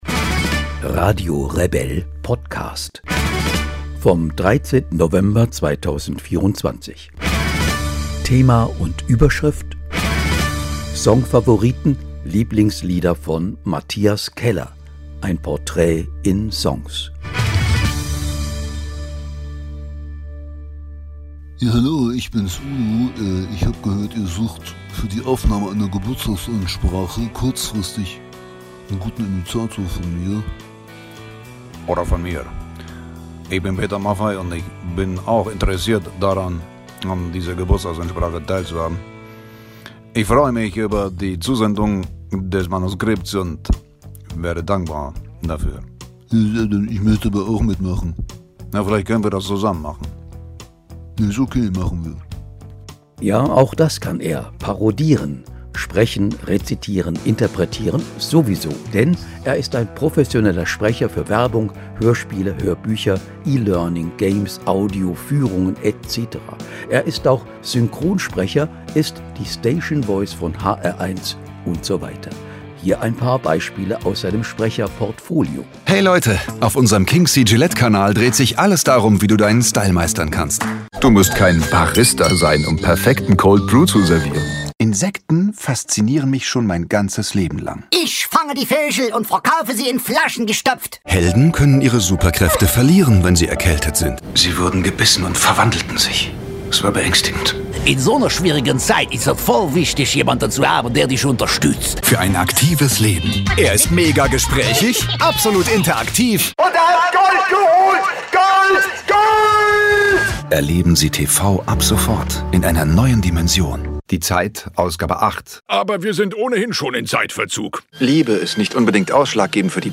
ein musikalisches Porträt in 10 Songs
Im ausführlichen Gespräch spricht er über seine Liebe zur Melancholie, redet offen über seine depressiven Verstimmungen und wie er damit umgeht und sich davon frei machen kann über glückliche Erfahrungen im privaten Umfeld wie in seiner Musik.